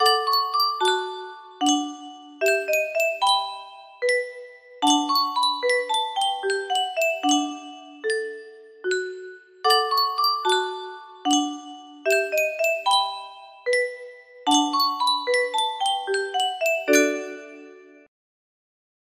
Yunsheng Music Box - Beautiful Dreamer 025Y music box melody
Full range 60